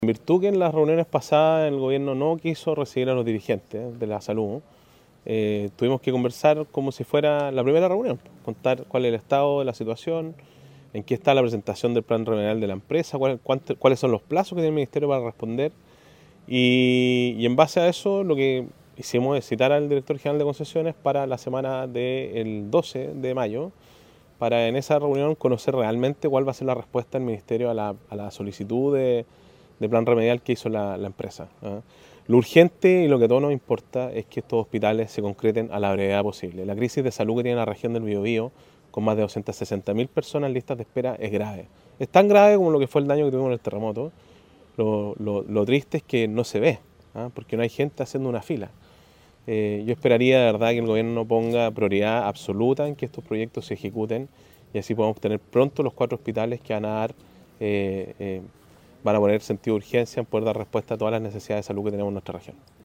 Al término de la actividad, el gobernador Sergio Giacaman llamó al gobierno a dar “prioridad absoluta a que estos proyectos se ejecuten” y relevó la gravedad de las listas de espera en la región, que afecta a más de 260 mil personas.